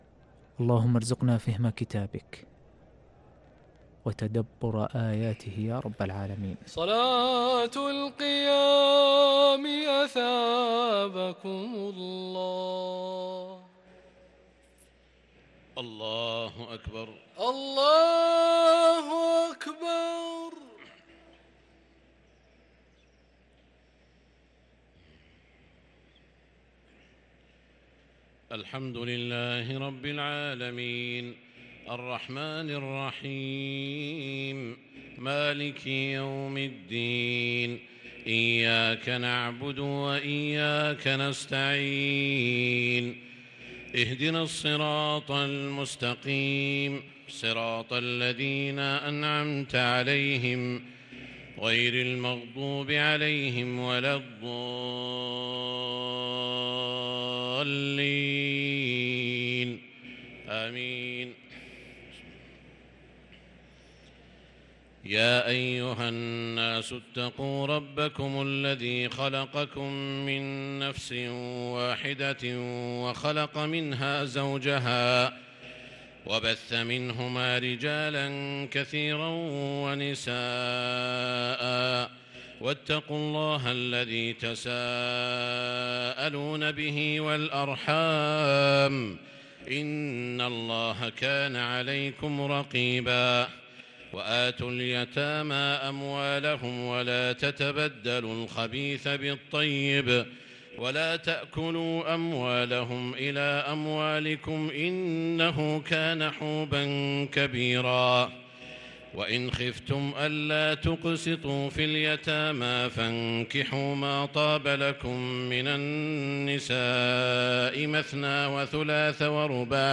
صلاة التراويح ليلة 6 رمضان 1443 للقارئ سعود الشريم - الثلاث التسليمات الأولى صلاة التراويح